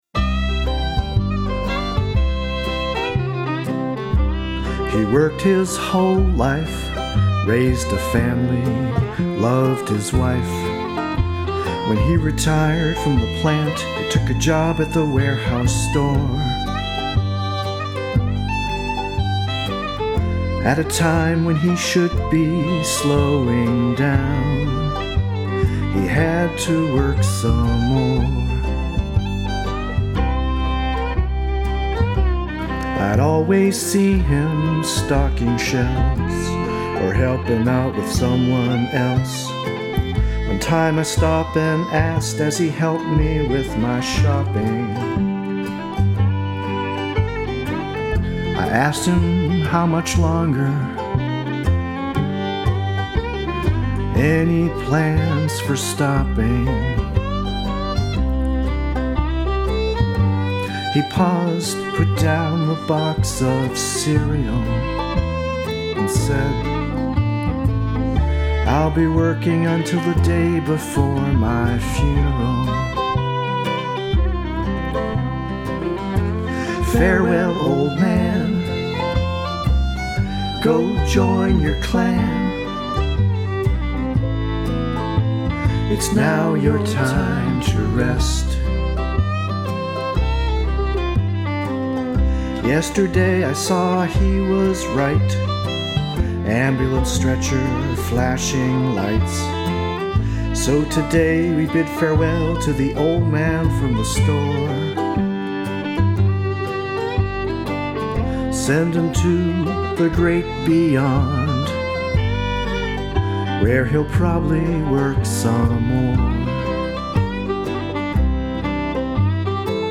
demo recording.